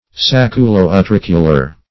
Search Result for " sacculo-utricular" : The Collaborative International Dictionary of English v.0.48: Sacculo-utricular \Sac`cu*lo-u*tric"u*lar\, a. (Anat.)
sacculo-utricular.mp3